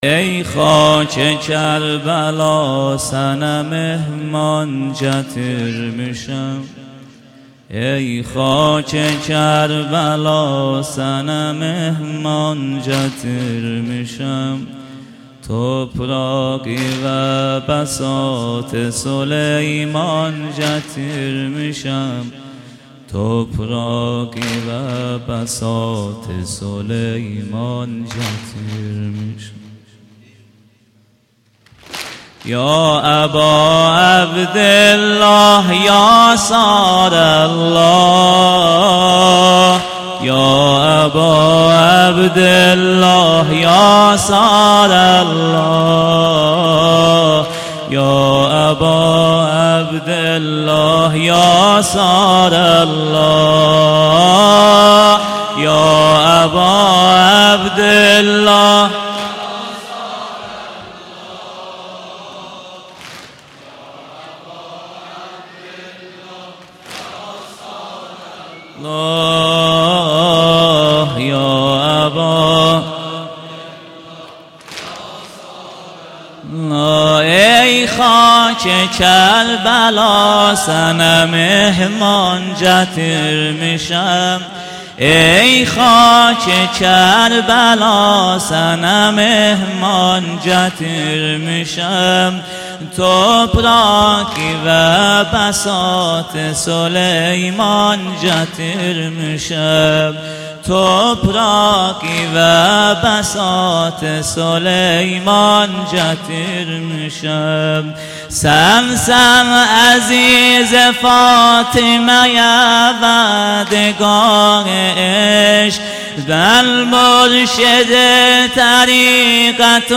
محرم ۹۱. شب سوم. بخش دوم سینه زنی